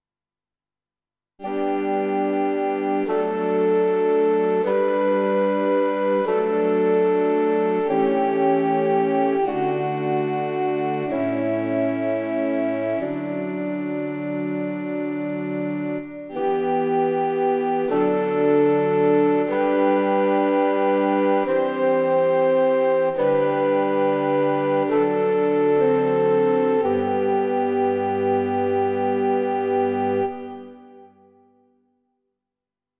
12. I SUONI - GLI STRUMENTI XG - GRUPPO "ORGAN"
29. Puff Organ
XG-02-29PuffOrgan.mp3